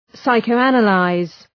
Προφορά
{,saıkəʋ’ænə,laız}